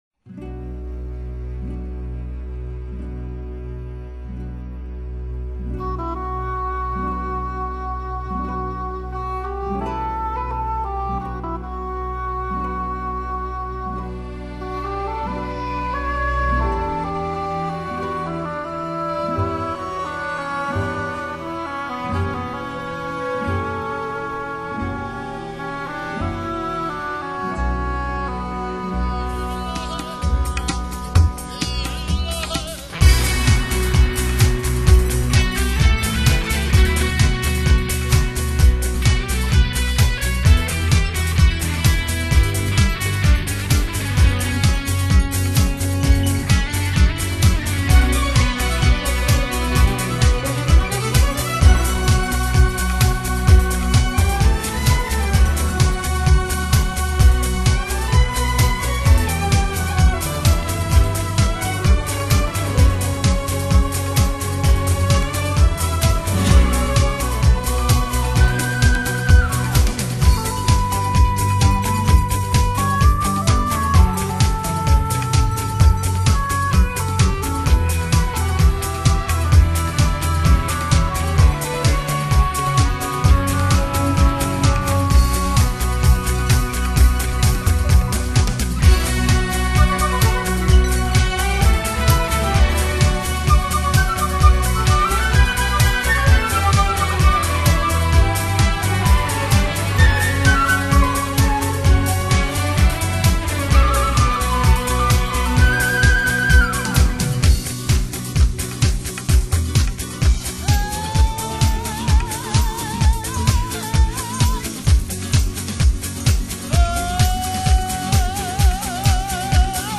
专辑在音乐类型上包罗万象，以古典音乐的基础上，电子乐、爵士乐、民乐等百花齐放、相得益彰。